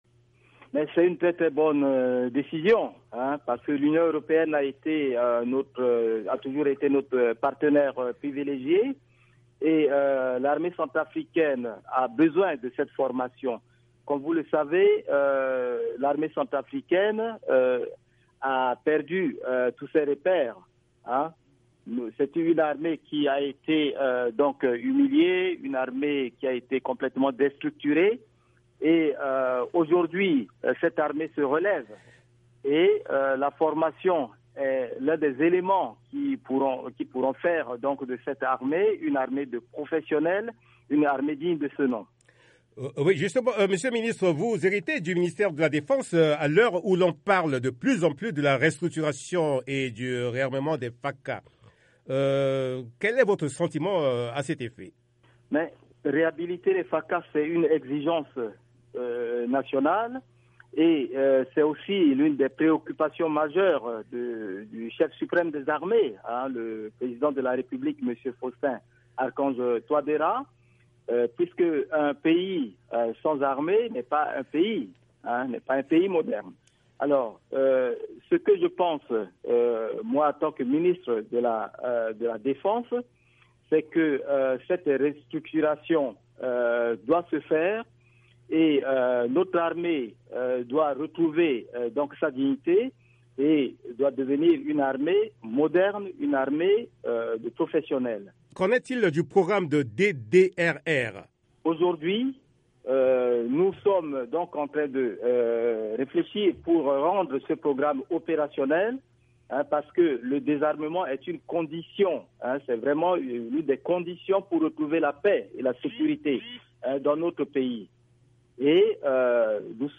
Aujourd’hui, cette armée se relève et la formation pourra faire d’elle une armée des professionnels, une armée digne de ce nom", indique à VOA Afrique, Joseph Yakete, ministre centrafricain de la Défense.